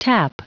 Prononciation du mot tap en anglais (fichier audio)
Prononciation du mot : tap